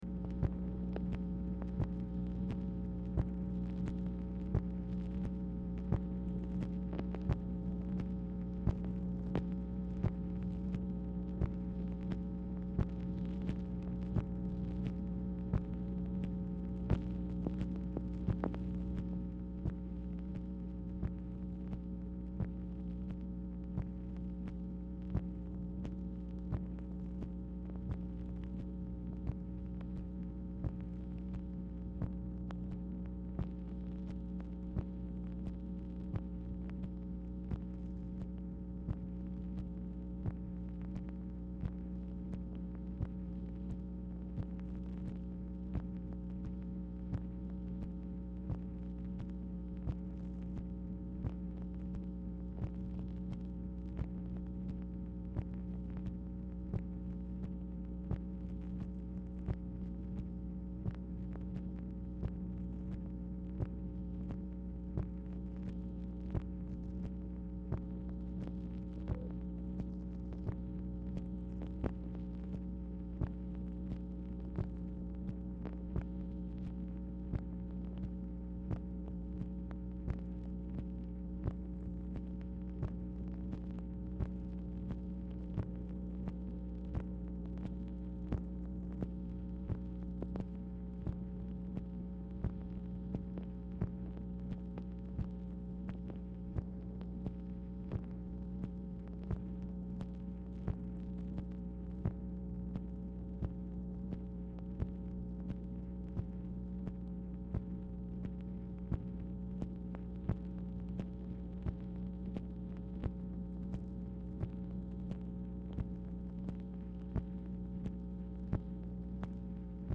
Telephone conversation # 11399, sound recording, MACHINE NOISE, 1/23/1967, time unknown | Discover LBJ
Format Dictation belt